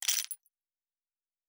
pgs/Assets/Audio/Fantasy Interface Sounds/Objects Small 02.wav at master
Objects Small 02.wav